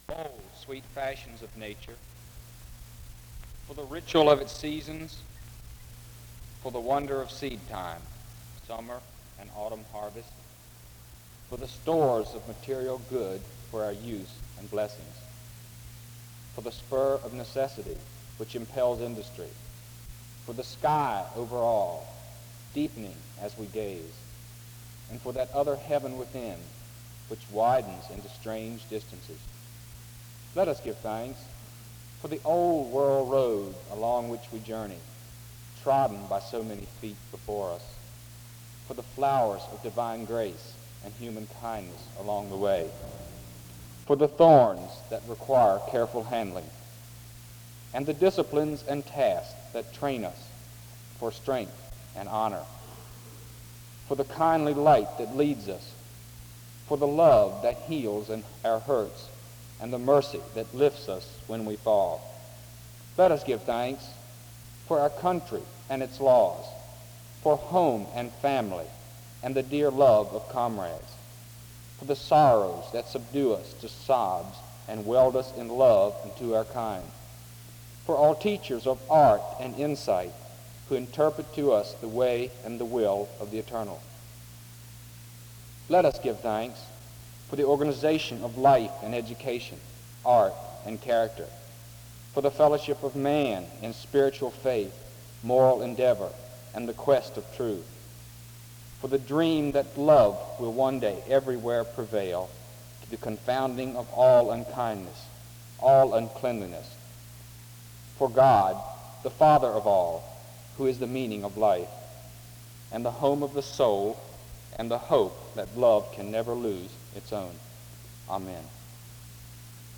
SEBTS Chapel - Senior Prayer Service October 1967
Students take turns praying over the school, nation, neighborhood, and missions in this prayer service.